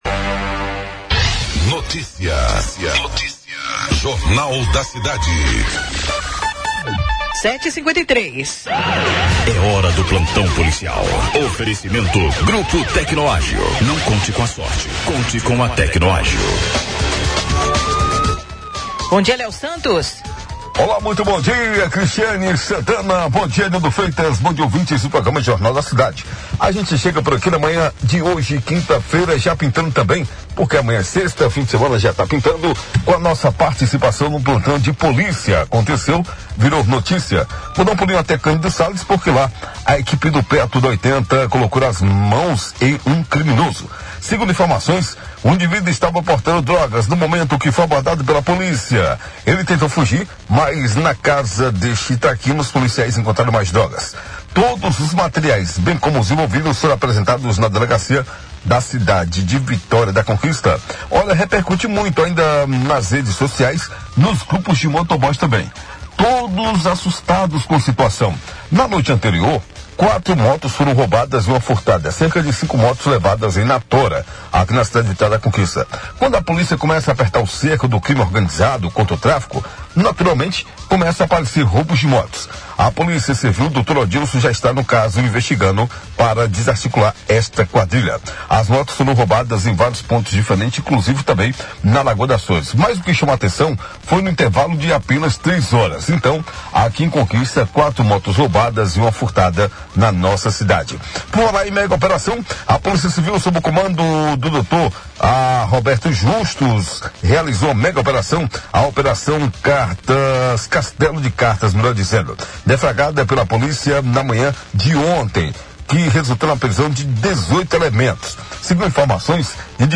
Furtos e roubos de motocicletas seguem causando prejuízos e muita preocupação em vitória da conquista. De acordo com as Ocorrências Policiais do Jornal da Cidade, via Rádio Clube de Conquista, desta quinta-feira (25), nas últimas 24 horas foram registradas quatro ações de criminosos, resultando em cinco motos levadas.